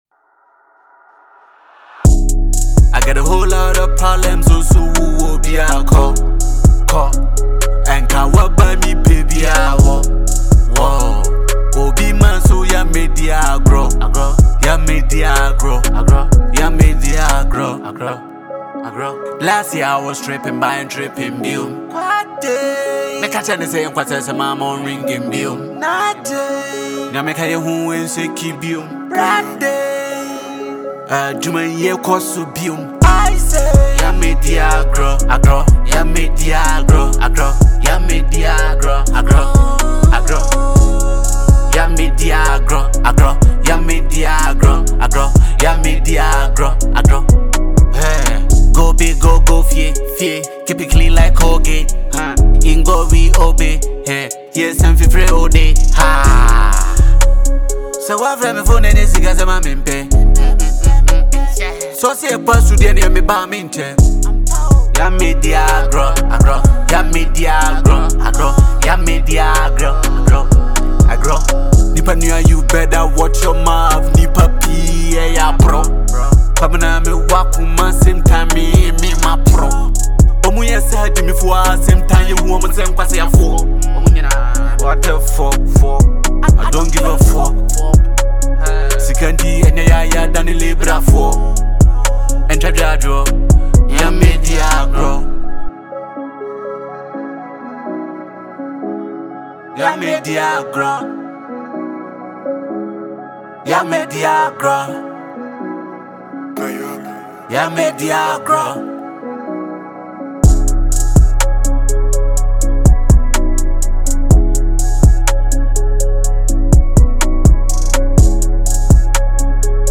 is a raw and energetic Ghanaian hip-hop/drill record
Genre: Hip-Hop / Drill